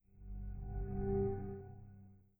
Windows X7 Shutdown.wav